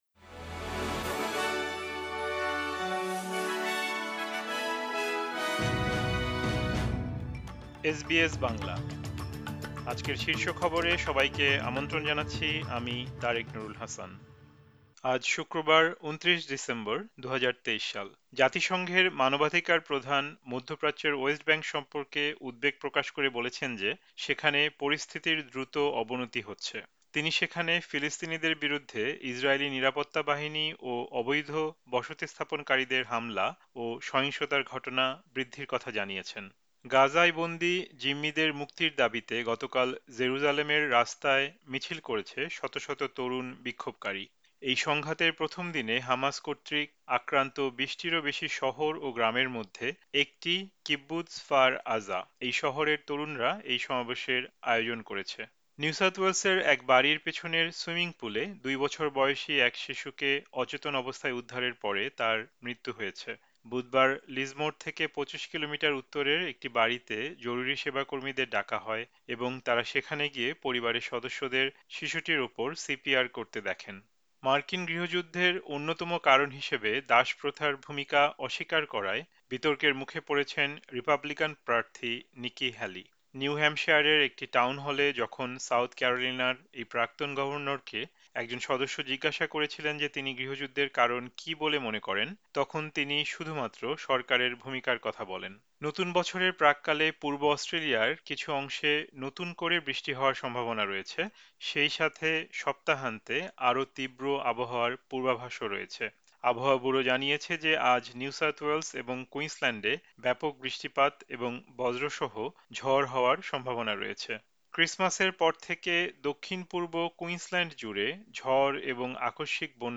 এসবিএস বাংলা শীর্ষ খবর: ২৯ ডিসেম্বর, ২০২৩